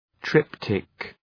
Προφορά
{‘trıptık}